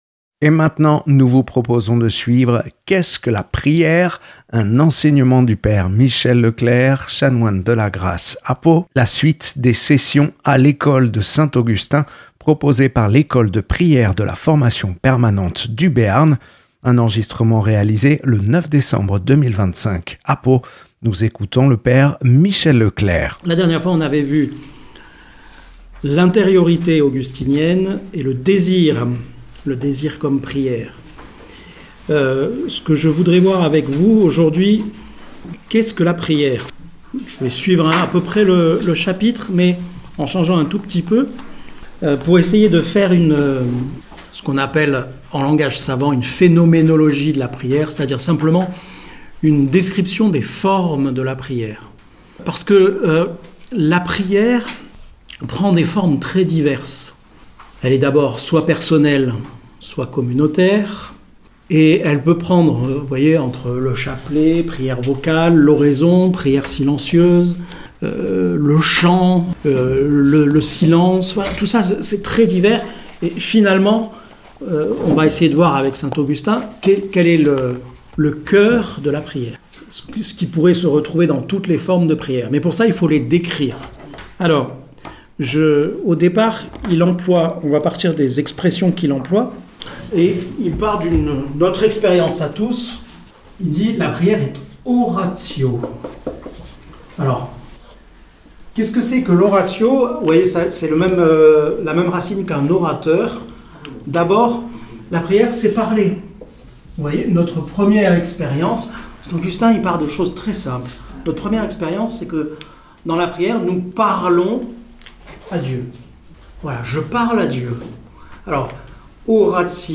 (9 décembre 2025 à Pau)